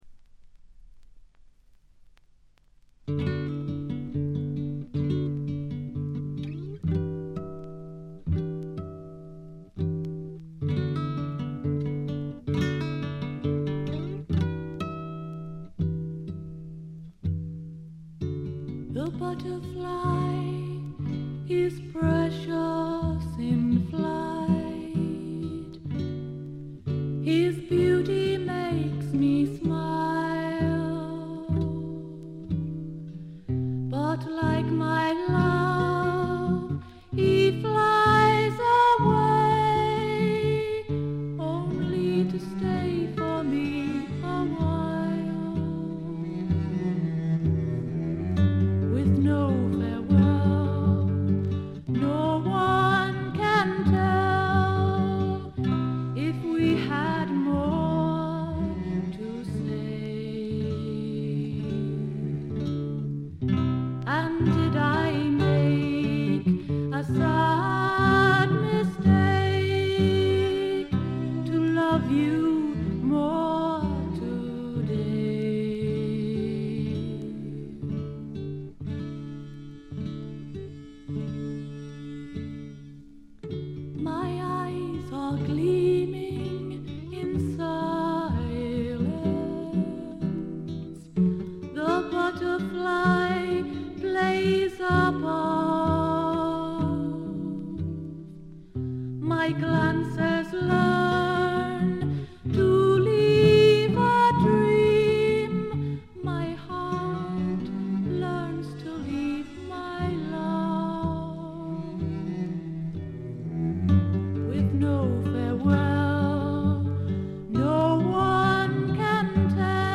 曲はすべて自作で、いかにも英国の女性シンガー・ソングライターらしいポップながら陰影のある曲が並びます。
試聴曲は現品からの取り込み音源です。
vocal, acoustic guitar